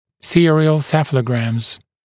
[‘sɪərɪəl ˈsefələgræmz][‘сиэриэл ˈсэфэлэгрэмз]серия цефалограмм, последовательно сделанные цефалограммы (т.е. телерентгенограммы головы в боковой проекции, сделанные через определенные промежутки времени для контроля роста пациента)